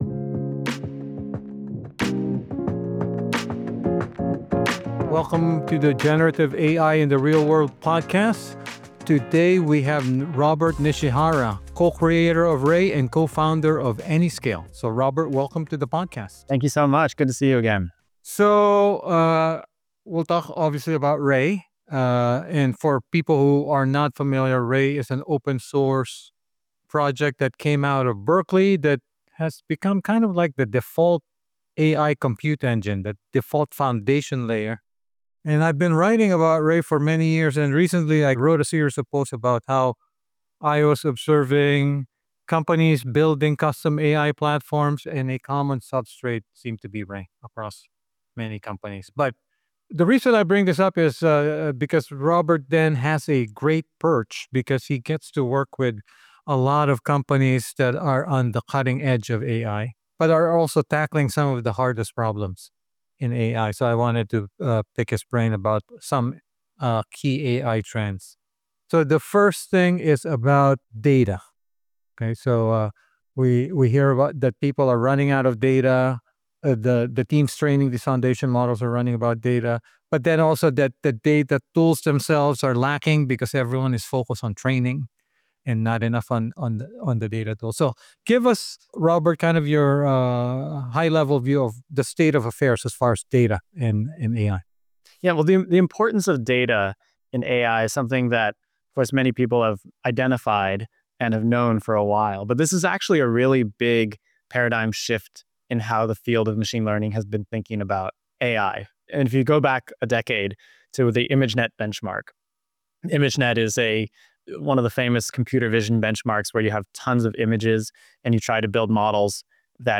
About the Generative AI in the Real World podcast: In 2023, ChatGPT put AI on everyone’s agenda.